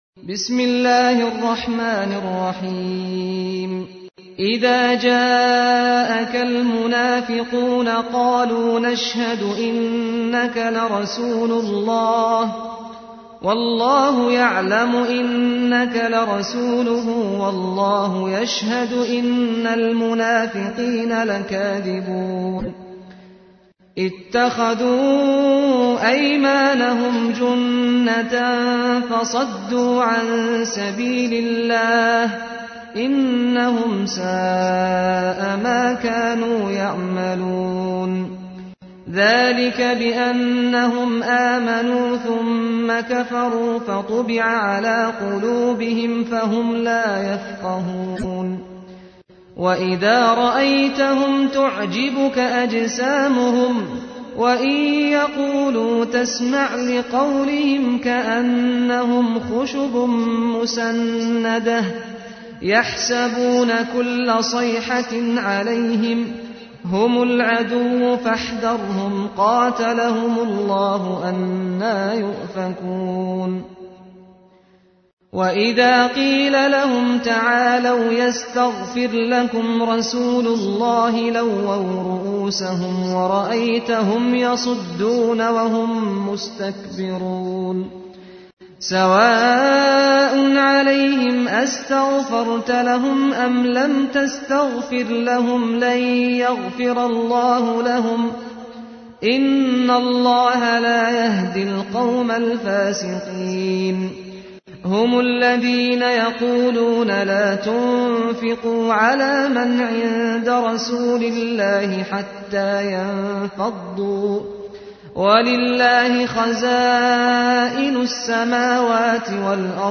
تحميل : 63. سورة المنافقون / القارئ سعد الغامدي / القرآن الكريم / موقع يا حسين